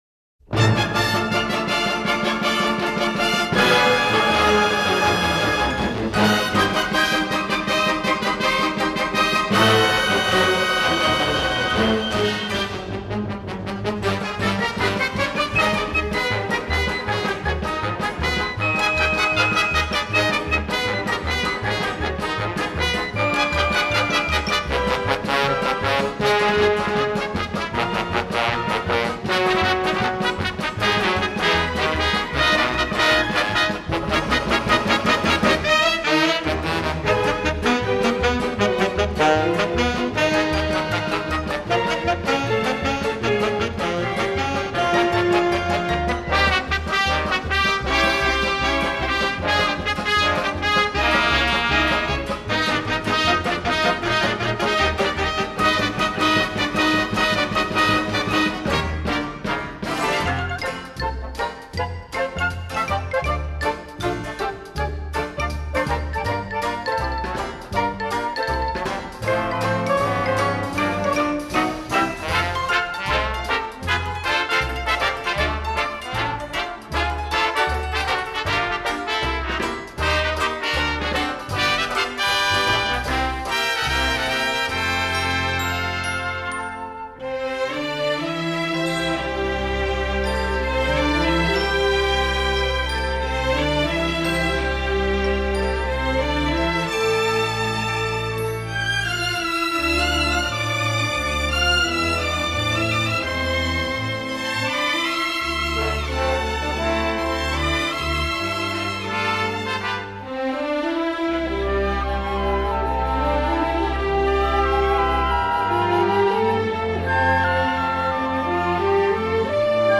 He thrilled at hearing the overture to a Broadway show
original cast album